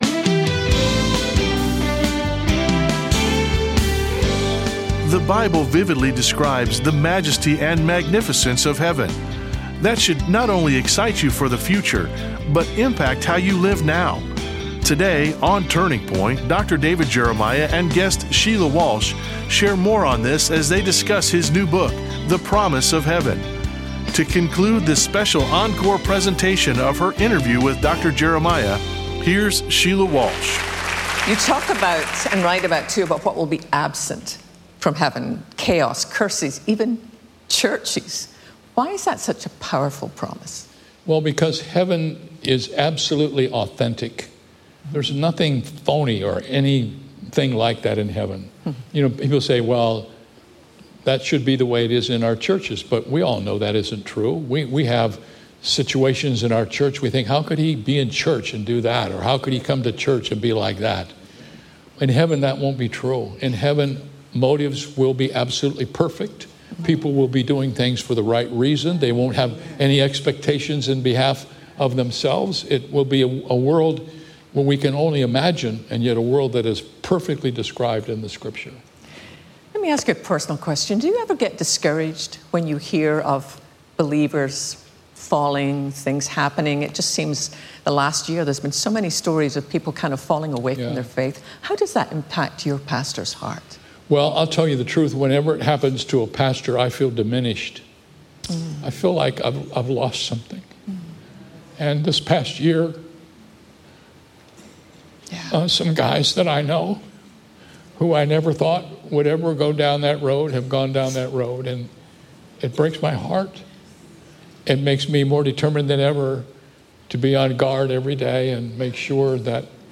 In this special interview, Dr. David Jeremiah sits down with Sheila Walsh to answer some of the most common and heartfelt questions about heaven and eternity. This honest and encouraging conversation brings clarity, comfort, and a deeper longing for our eternal home.